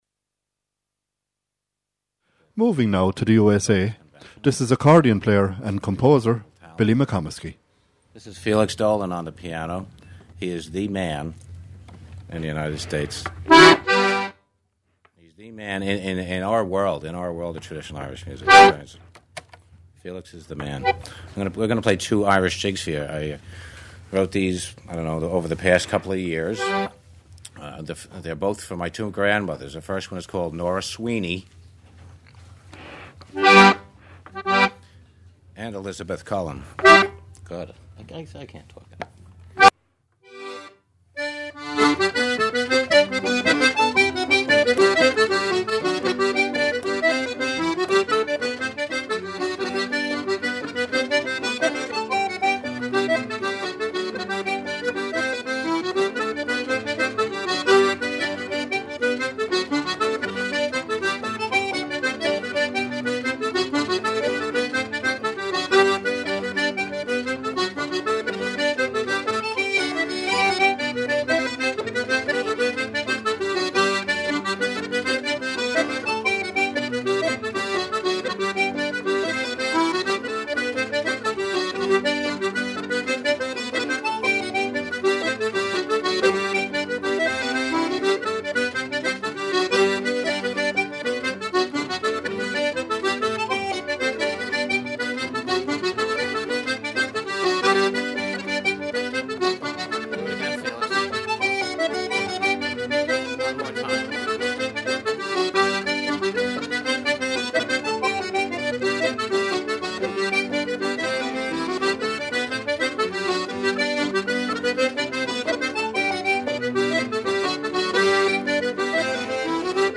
reels
concert flute
piano was recorded in April 2004
button accordion